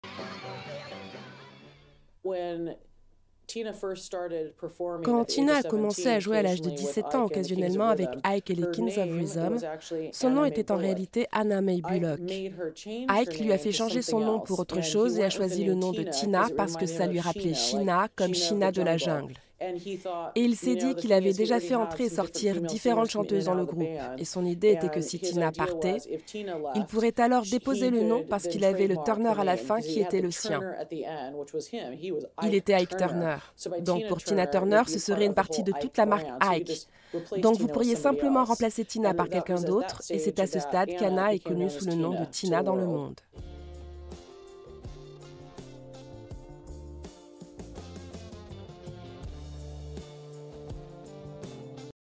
Voice Over
ton neutre